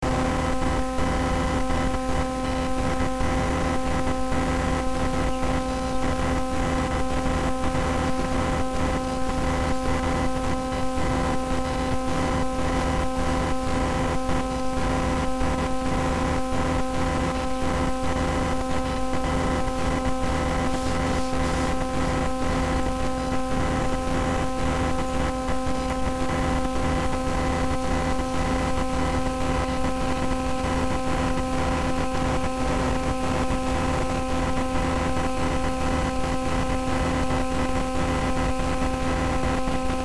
Â Set up induction loop close to extension socket with microphone. Â Talk into the microphone to see if the ground of power supply will transmit your words into the recording computer through electromagnetic induction.